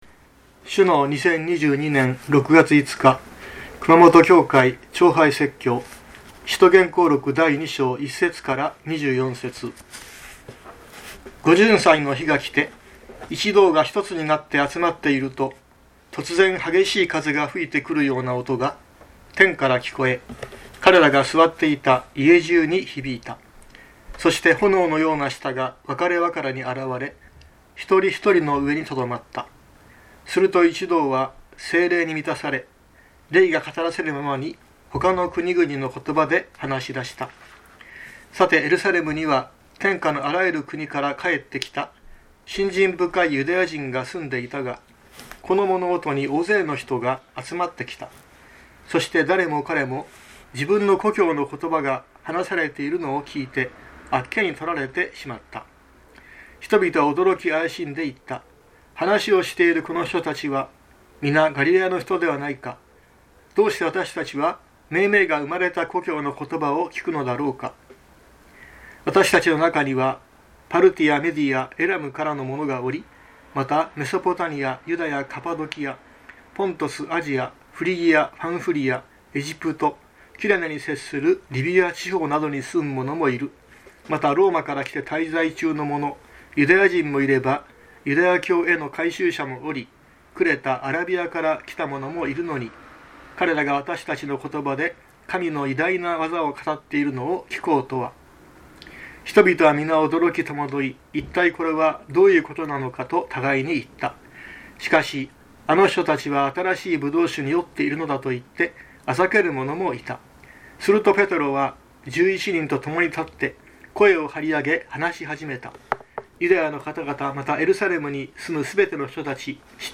2022年06月05日朝の礼拝「聖霊の降臨」熊本教会
熊本教会。説教アーカイブ。